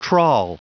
Prononciation du mot trawl en anglais (fichier audio)
Prononciation du mot : trawl